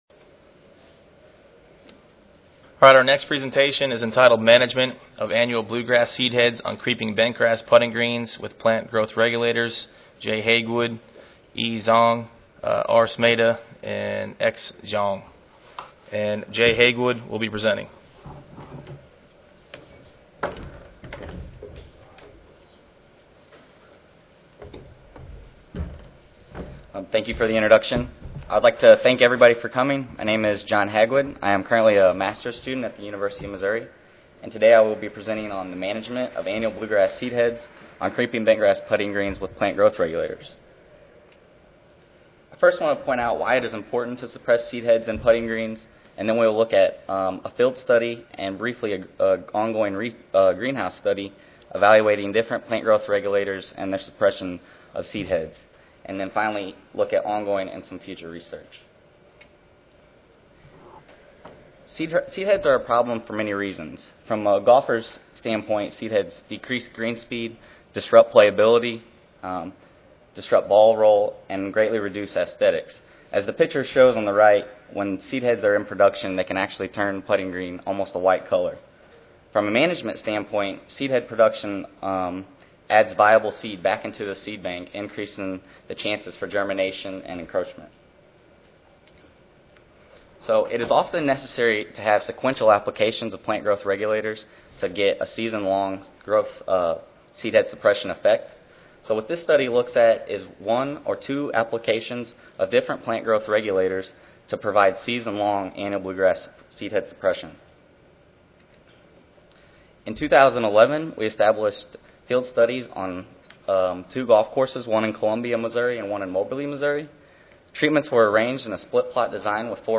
C05 Turfgrass Science Session: Student Oral Competition: Weed Control & Diseases In Turfgrass (ASA, CSSA and SSSA Annual Meetings (San Antonio, TX - Oct. 16-19, 2011))
Columbia Recorded Presentation Audio File